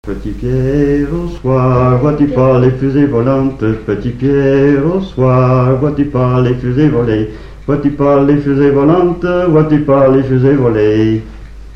Chants brefs - A danser
Fonction d'après l'analyste danse : mazurka ;
Catégorie Pièce musicale inédite